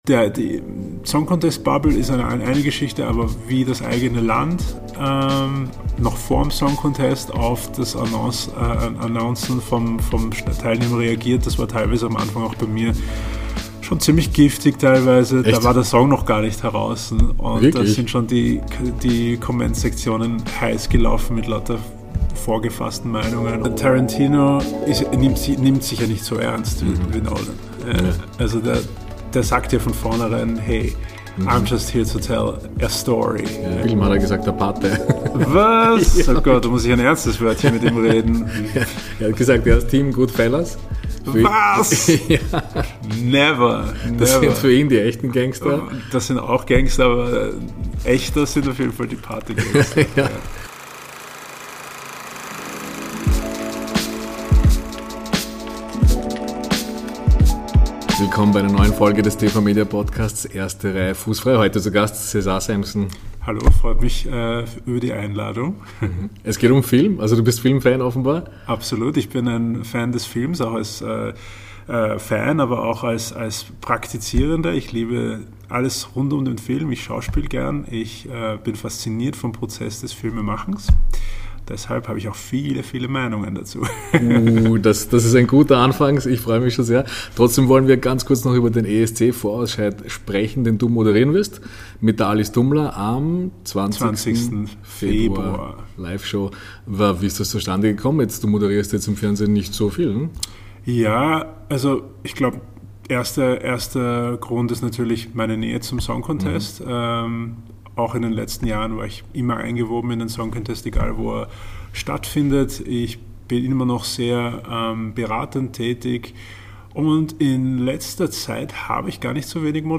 Cesár Sampson, Dritter beim ESC 2018, plaudert mit uns über das größte Musik-Event der Erde, nennt uns aber auch seine Lieblingsfilme und muss dabei Musikerkollege Thorsteinn Einarsson heftig widersprechen.